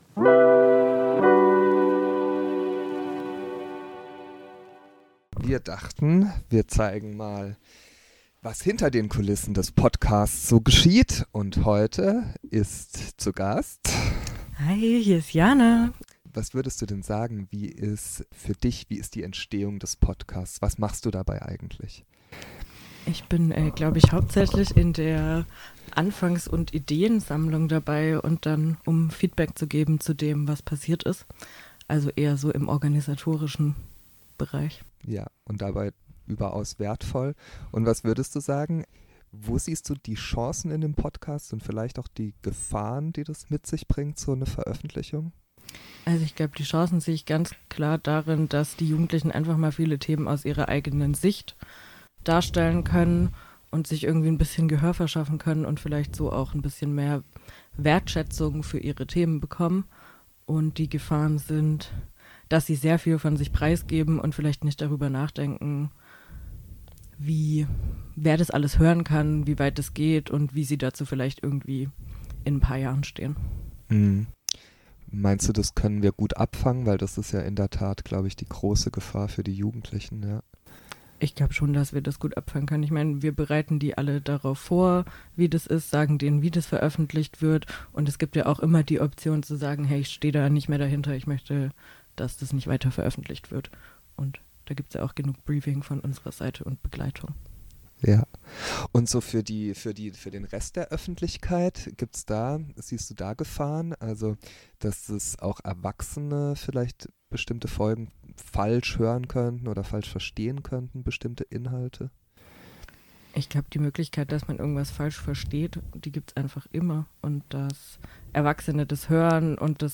In dieser Ausgabe stellt sich das Sozialpädagogen-Team hinter der Produktion vor.